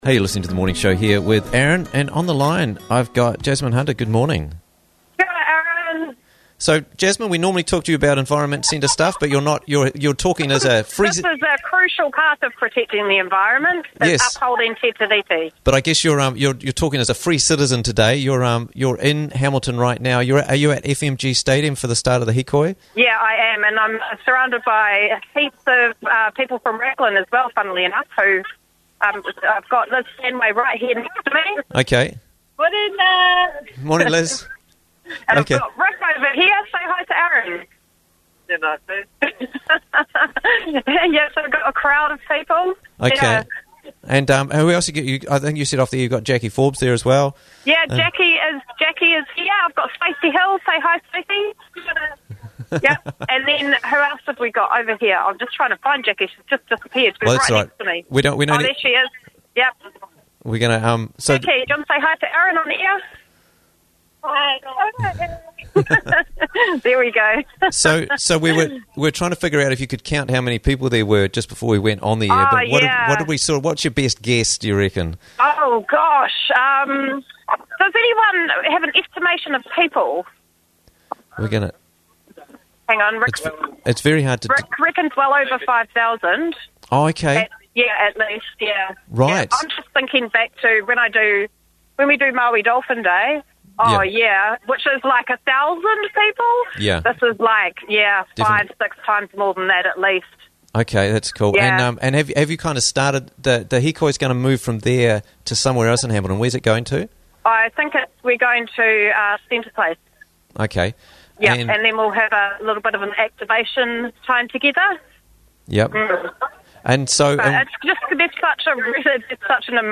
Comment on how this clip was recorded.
Live Report From Toitu Te Tiriti Hikoi